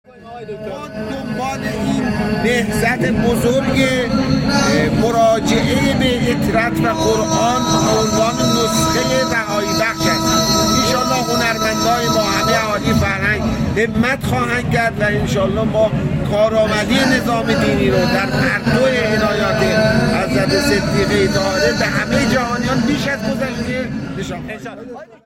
اسماعیلی در جمع خبرنگاران: